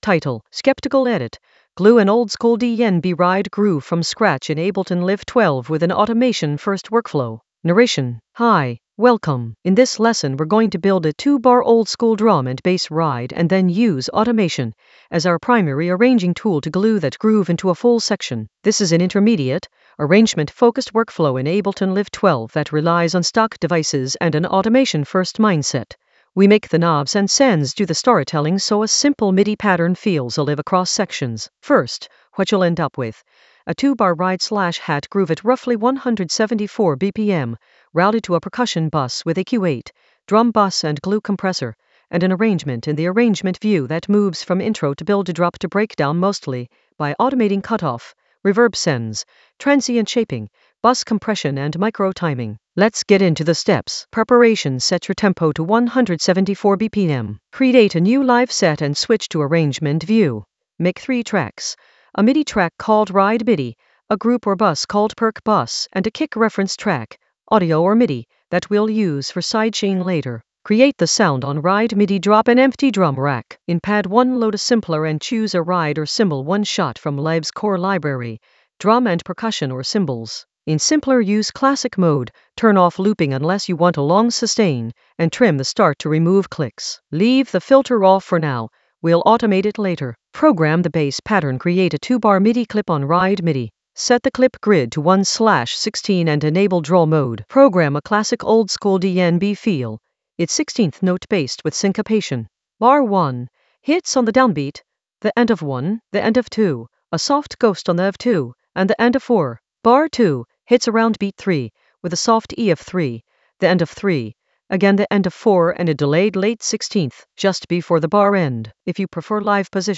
An AI-generated intermediate Ableton lesson focused on Skeptical edit: glue an oldskool DnB ride groove from scratch in Ableton Live 12 with automation-first workflow in the Arrangement area of drum and bass production.
Narrated lesson audio
The voice track includes the tutorial plus extra teacher commentary.